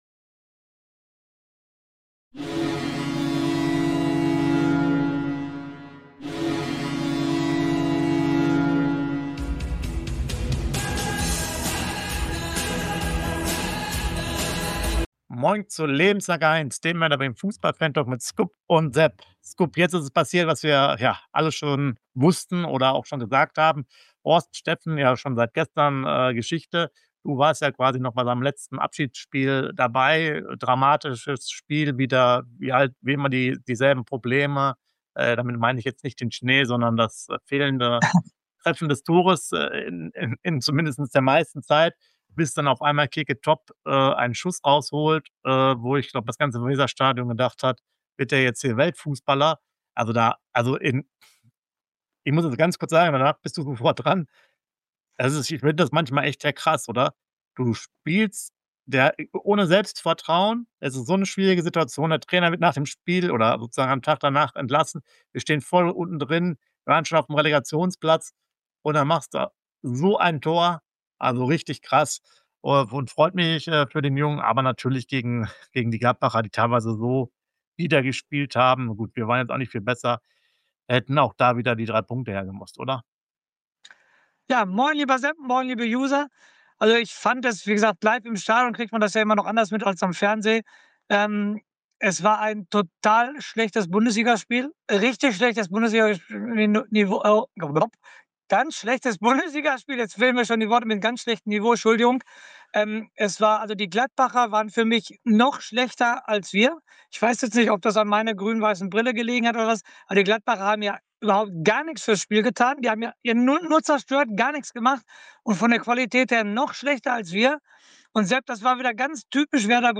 Fantalk